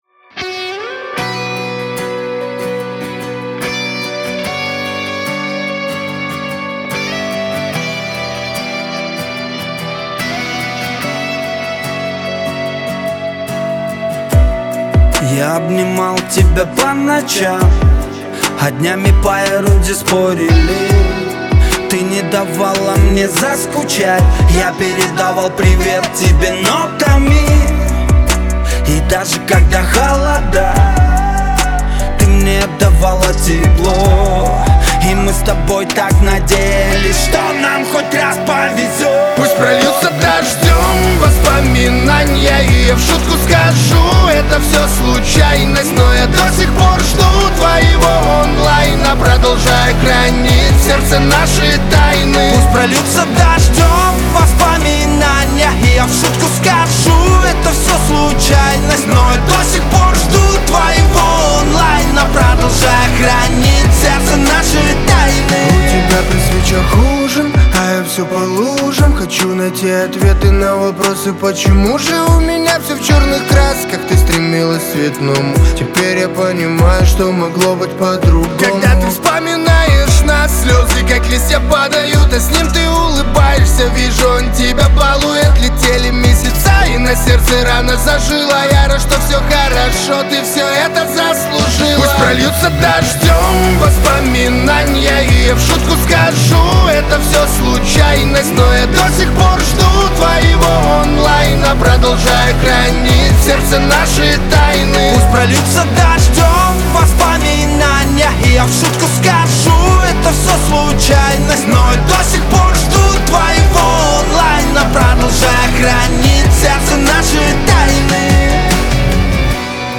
Жанр: Поп-музыка на русском языке